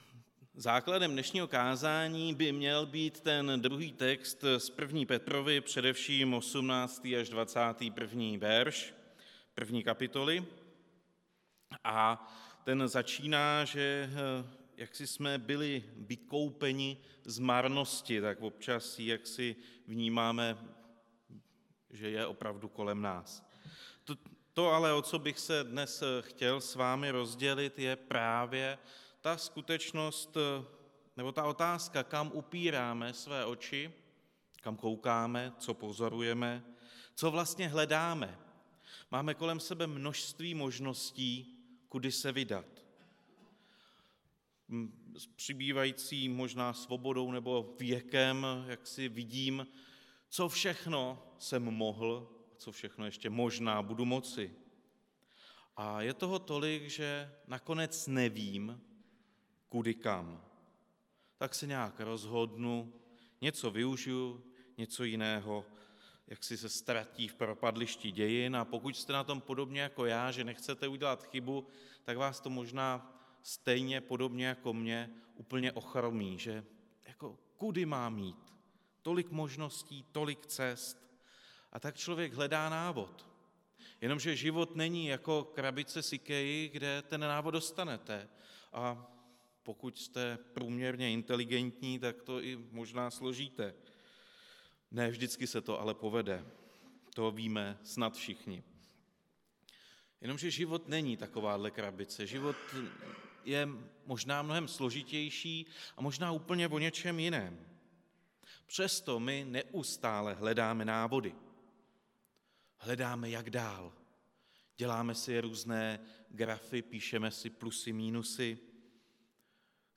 Kázání
Událost: Kázání